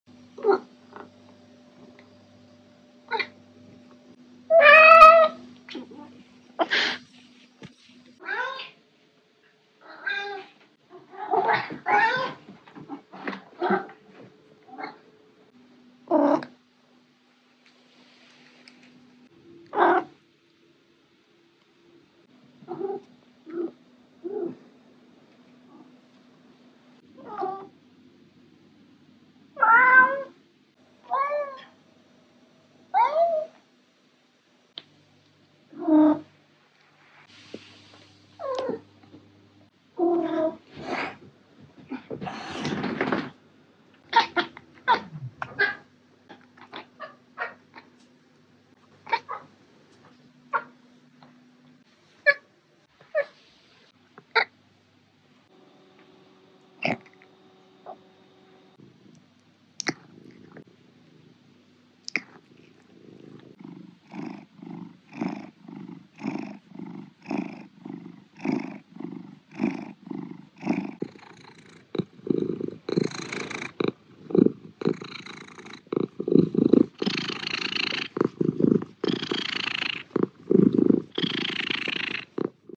🔊 Maine Coon Cat Sounds: sound effects free download
Mp3 Sound Effect 🔊 Maine Coon Cat Sounds: Female 🖤 vs Male 🧡 (Meows, Chirps, Mrrps, Purrs) 😻 Maine Coon cats have very extensive vocabularies. They are quite chatty and their sounds are more advanced than those of regular cats.
Even their purring sounds different!